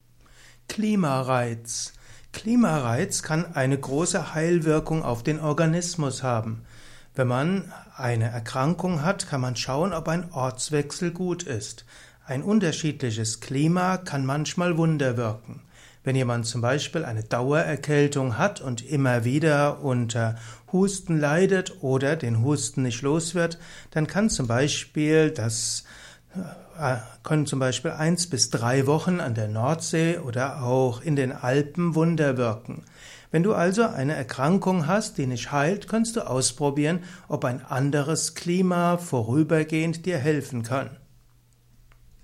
Vortragsaudio rund um das Thema Klimareiz. Erfahre einiges zum Thema Klimareiz in diesem kurzen Improvisations-Vortrag.